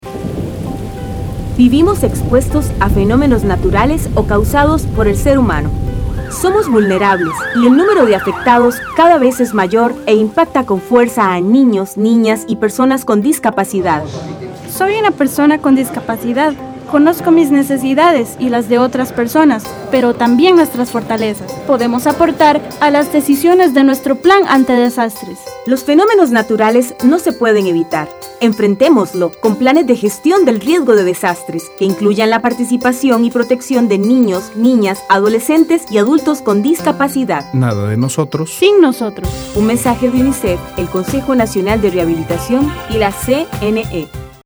[Anuncio de radio].